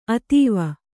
♪ atīva